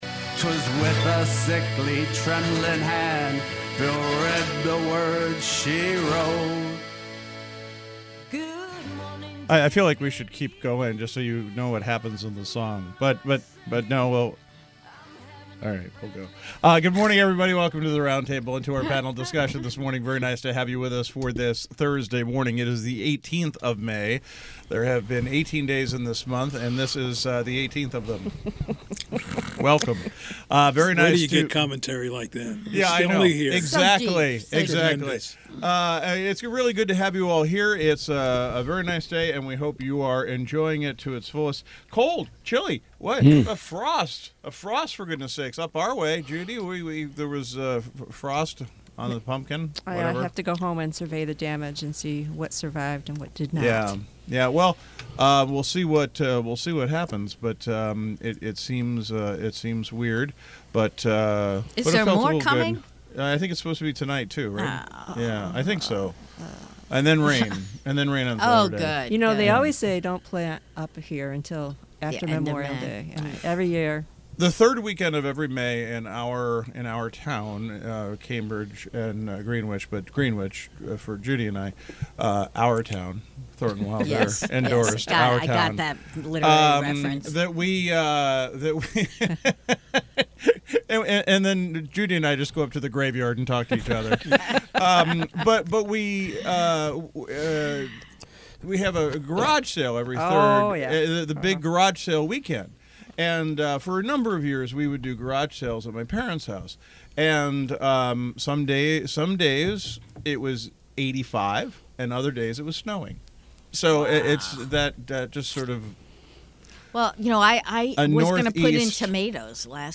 The Roundtable Panel: a daily open discussion of issues in the news and beyond.